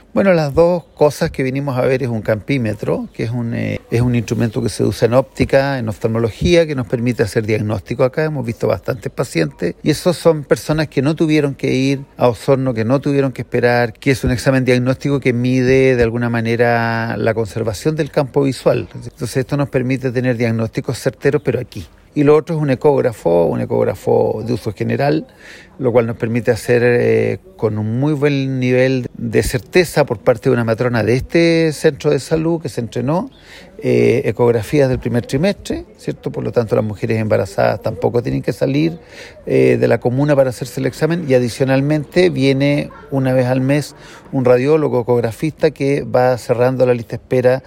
Ambas adquisiciones fueron destacadas por el Director del Servicio de Salud de Osorno, Rodrigo Alarcón, quien visitó Puaucho resaltando que esfuerzo que realiza el personal de salud, pues la comuna tiene dificultades que son intrínsecas a sus características territoriales por lo que se comprometió a realizar un proceso de planificación, para seguir acercando la salud a la comunidad.